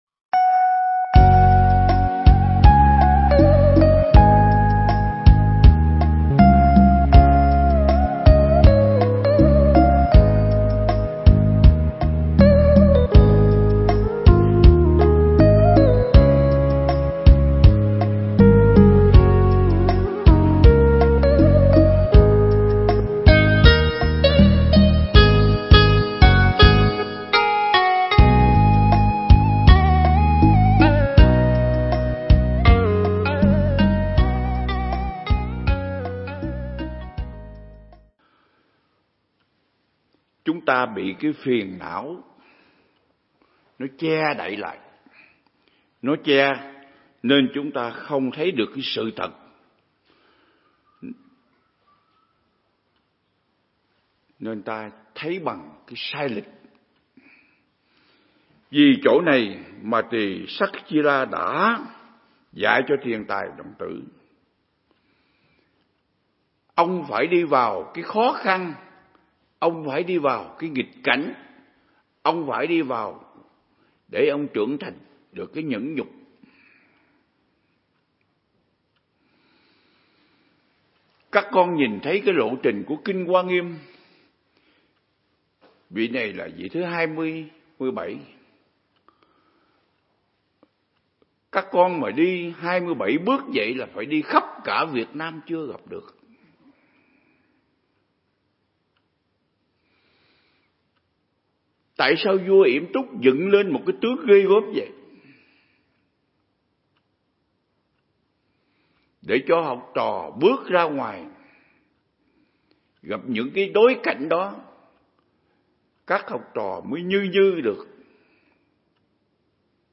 Mp3 Pháp Thoại Ứng Dụng Triết Lý Hoa Nghiêm Phần 53
giảng tại Viện Nghiên Cứu Và Ứng Dụng Buddha Yoga Việt Nam